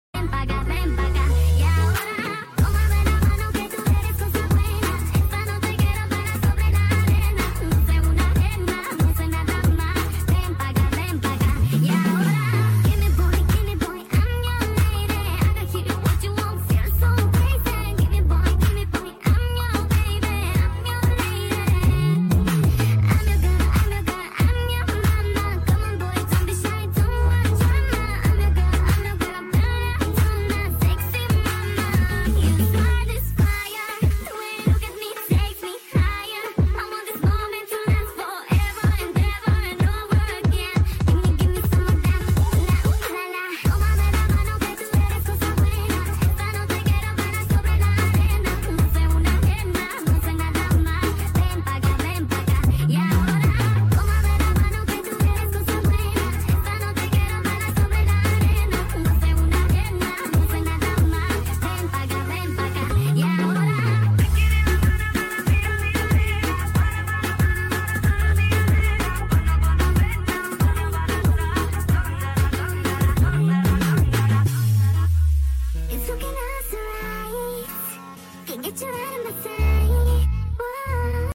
Boost music 🎵 Bass boost